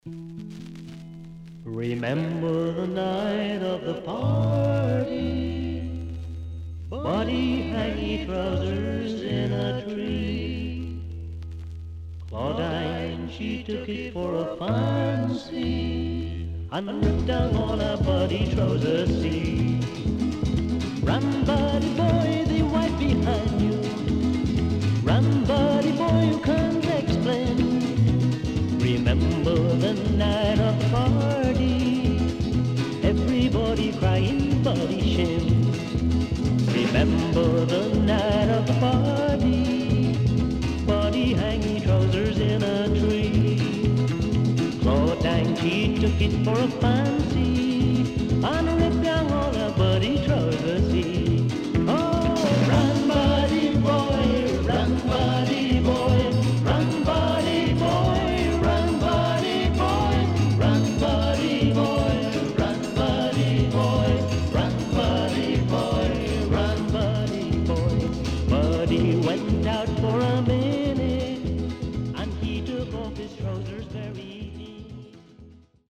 HOME > SKA / ROCKSTEADY  >  CALYPSO
Good Calypso
SIDE A:少しチリノイズ入りますが良好です。